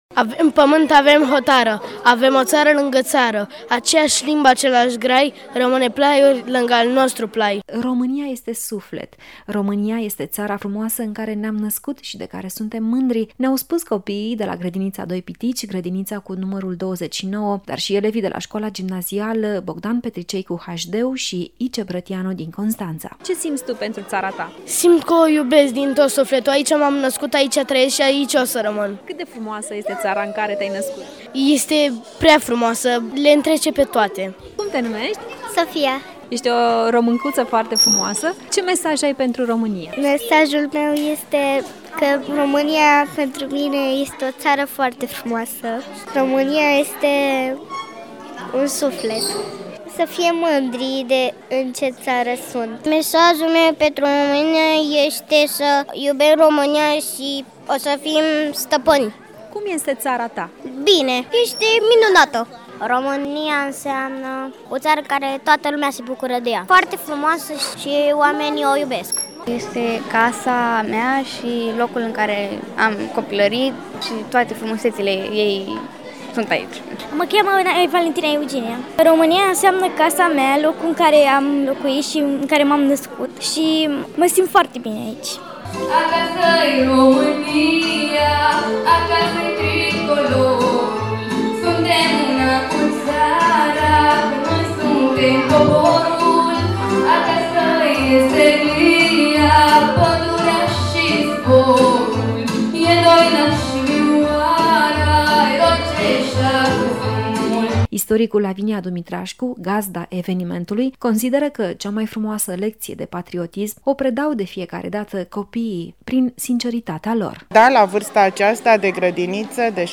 Zeci de prichindei de la grădinițe și școli au recitat poezii și au cântat pentru țara lor de glorii, țara lor de dor, în cadrul tradiționalului simpozion organizat de MINAC.
Zeci de prichindei de la grădinițe și școli din Constanța au sărbătorit Ziua României în avans, în cadrul unui Simpozion organizat de Muzeul de Istorie Națională și Arheologie Constanța.